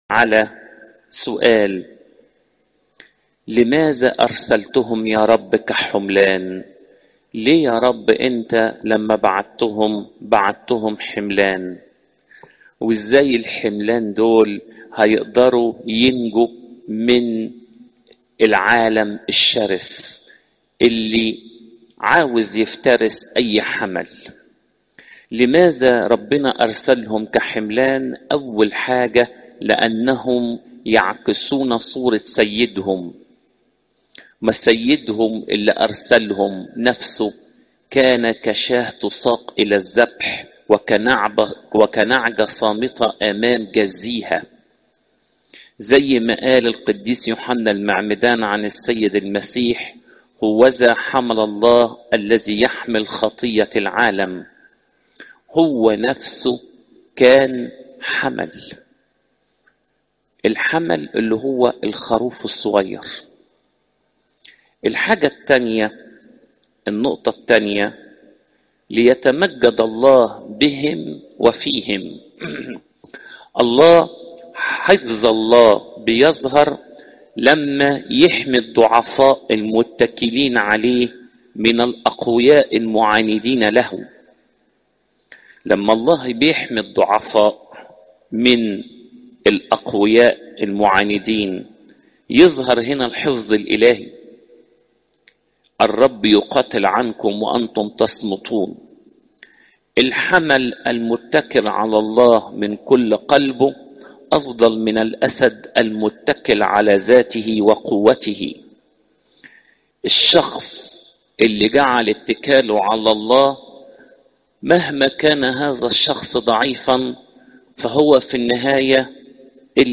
عظات قداسات الكنيسة (لو 10 : 1 - 20)